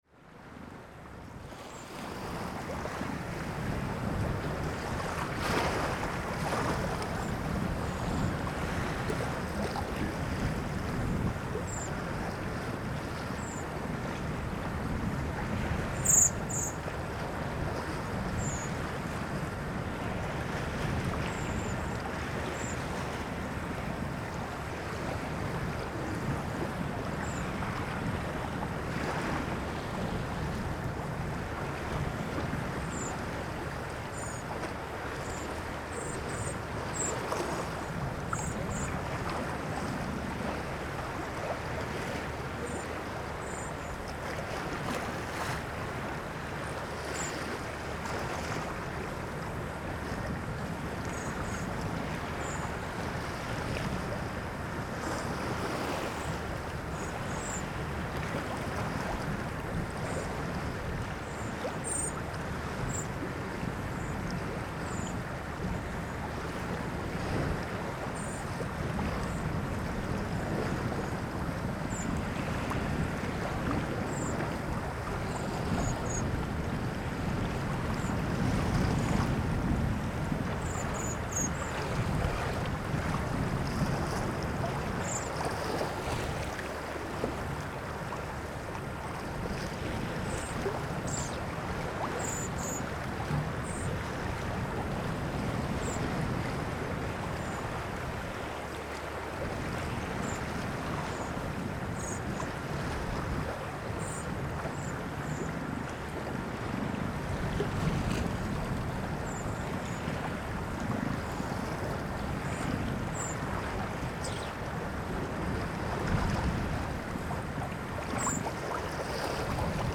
Thousands of Thrushes or a few waders fighting it´s way through mist and storm,
PFR08214, thrushes on migration, North Sea